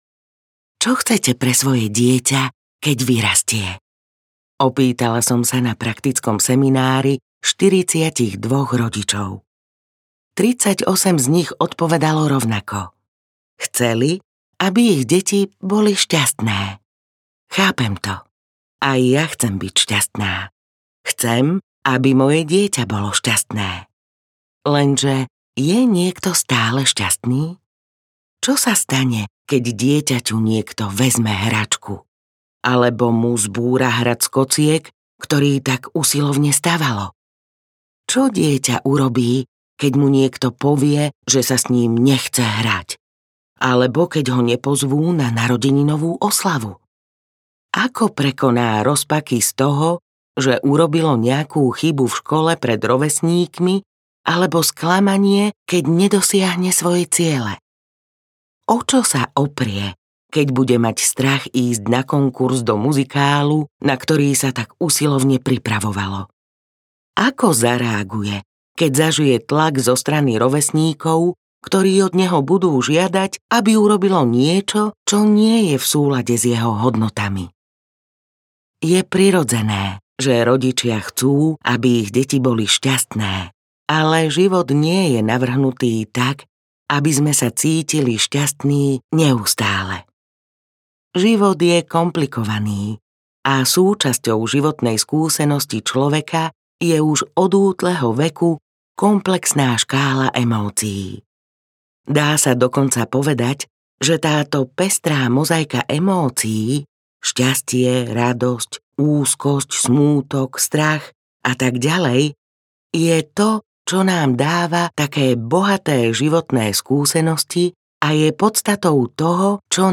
Malí ľudia, veľké emócie audiokniha
Ukázka z knihy
mali-ludia-velke-emocie-audiokniha